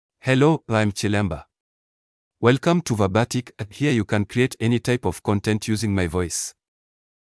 MaleEnglish (Kenya)
Chilemba — Male English AI voice
Chilemba is a male AI voice for English (Kenya).
Voice sample
Listen to Chilemba's male English voice.
Chilemba delivers clear pronunciation with authentic Kenya English intonation, making your content sound professionally produced.